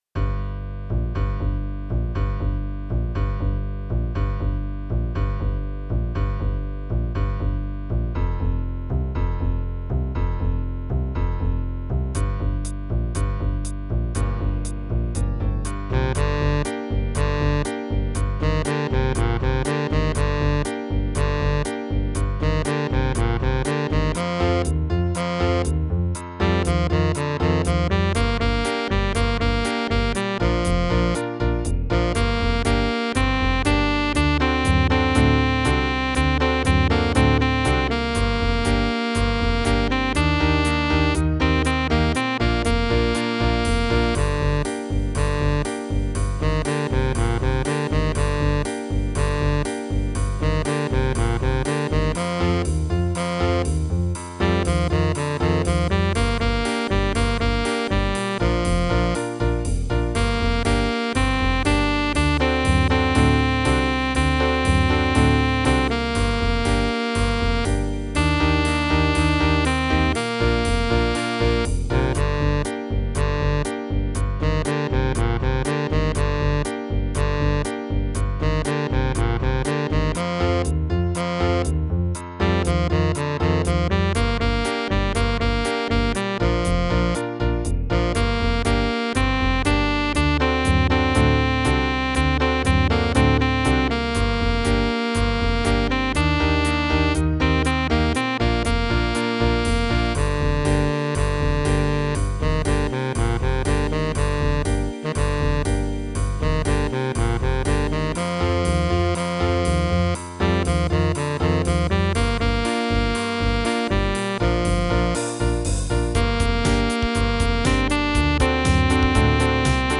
• Le fichier son, AVEC la mélodie ==>